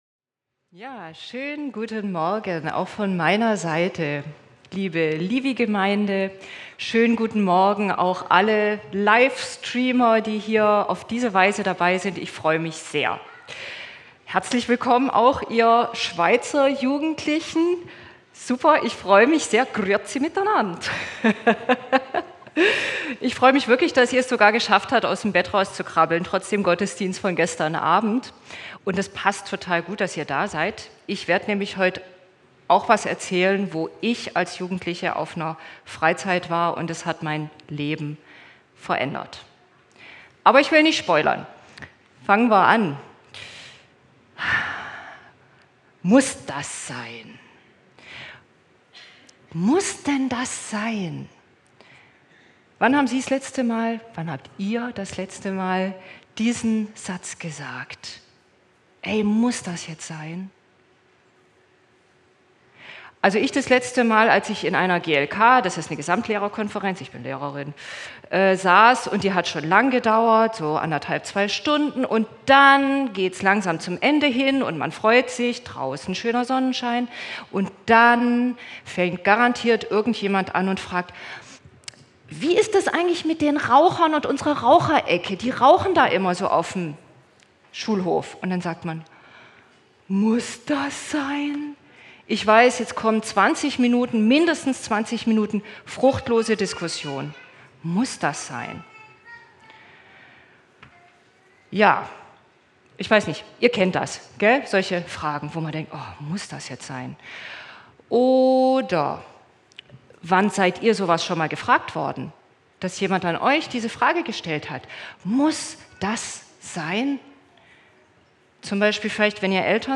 Predigt als Audio